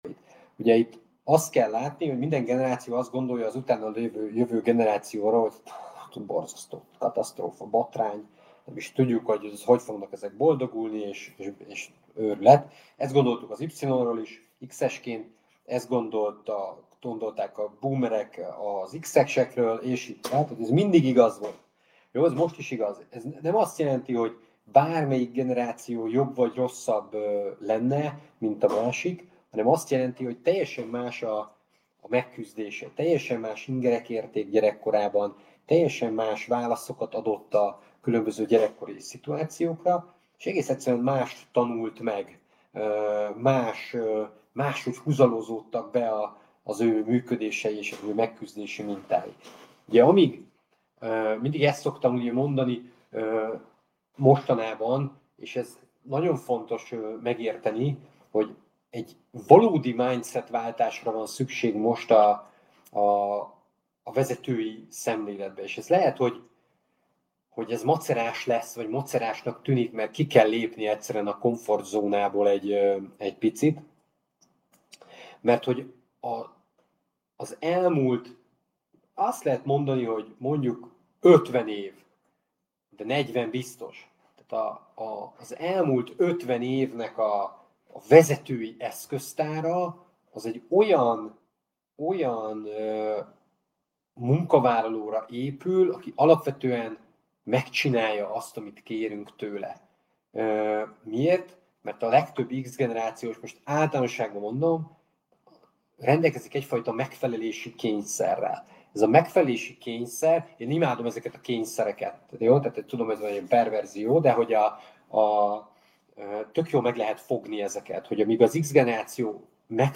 A rövid hanganyag segítségével most izelítőt kaphatsz a mesterkurzusból.